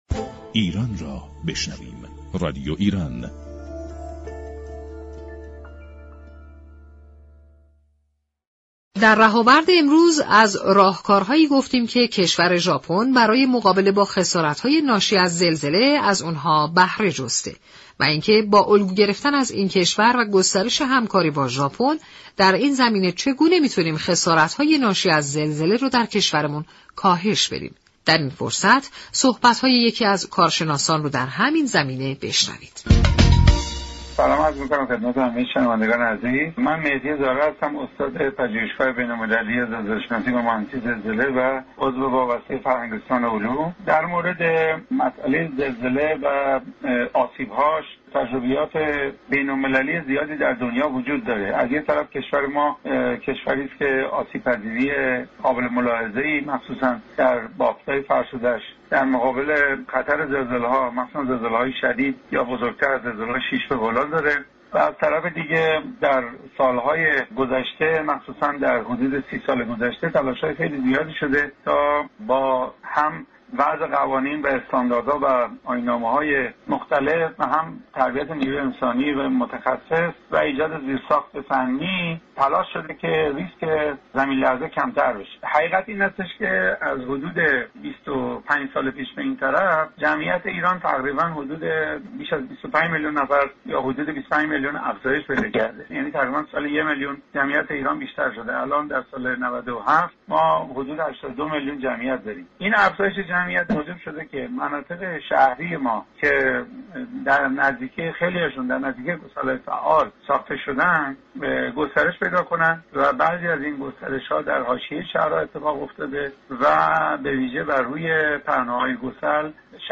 گفت و گو با رادیو ایران